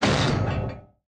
railgun-turret-rotation-stop.ogg